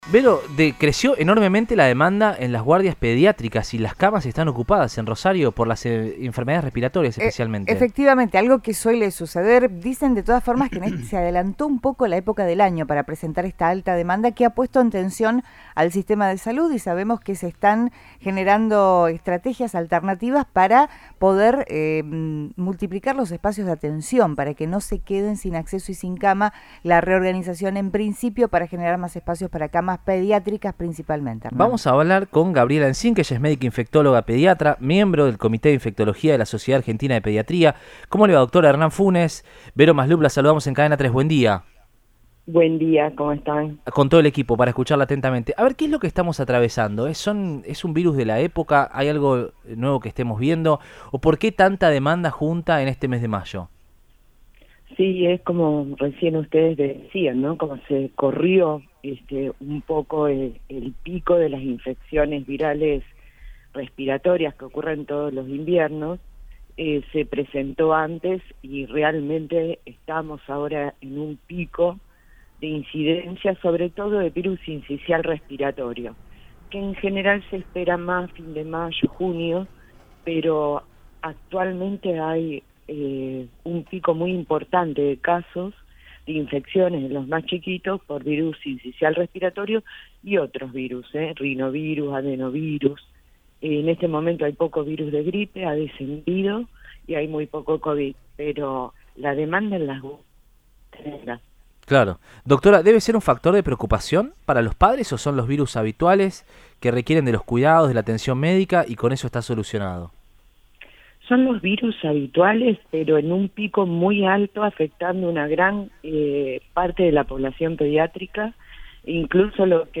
dialogó con Radioinforme 3 de Cadena 3 Rosario y brindó un panorama de la situación.